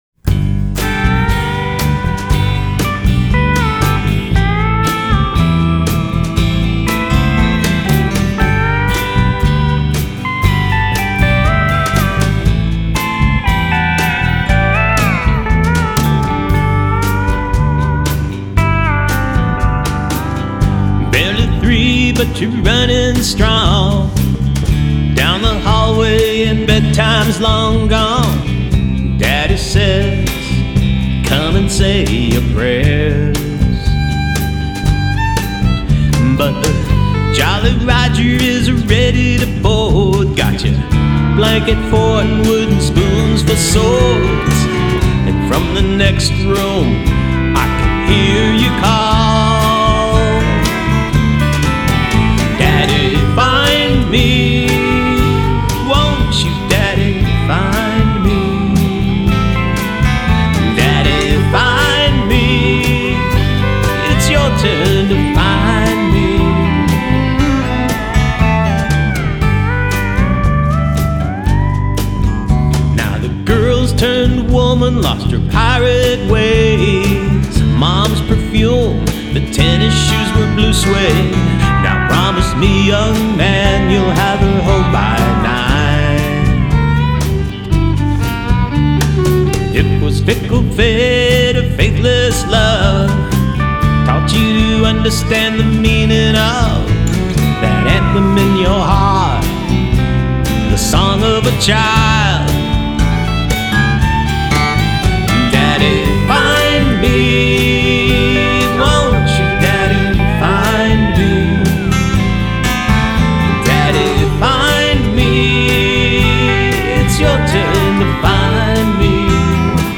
vocals, Telecaster guitar